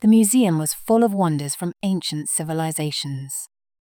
Kiejtés: /ˈwʌn.dər/